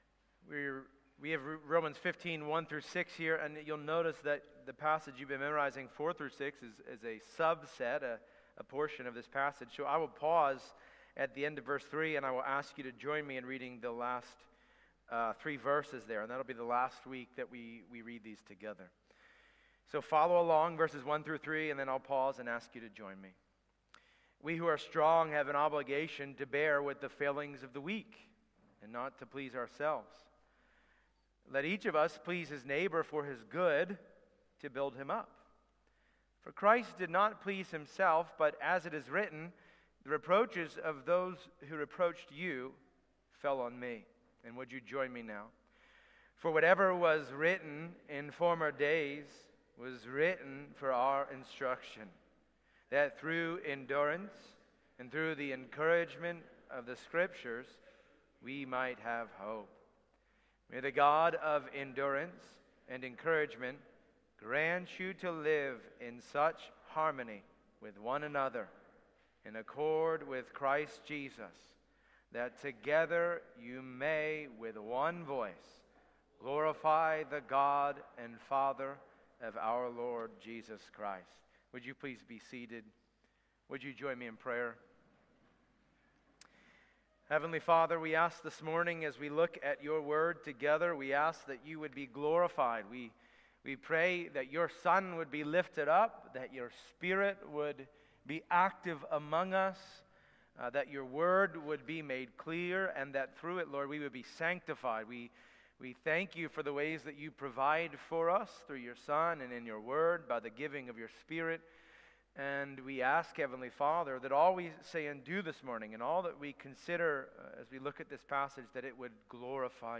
Our sermons every week are from our church service in Forest, VA.